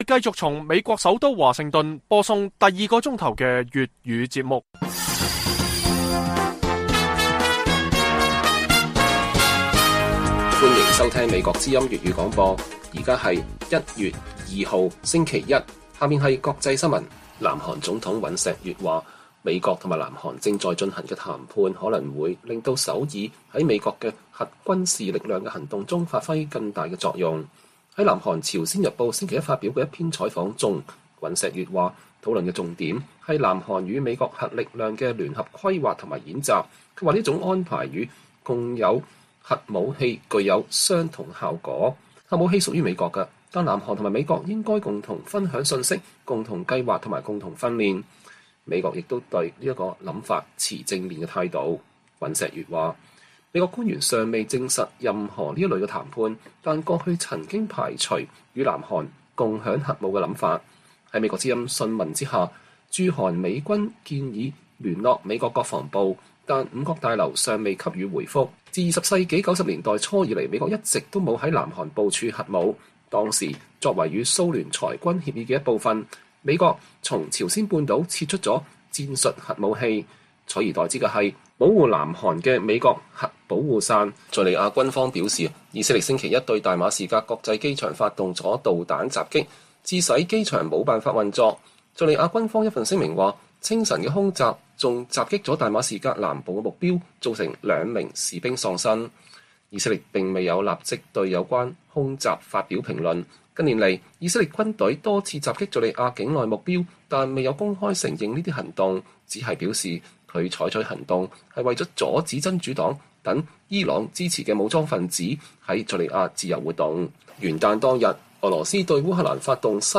粵語新聞 晚上10-11點: 南韓總統尹錫悅說美韓正在討論核軍事力量規劃與演習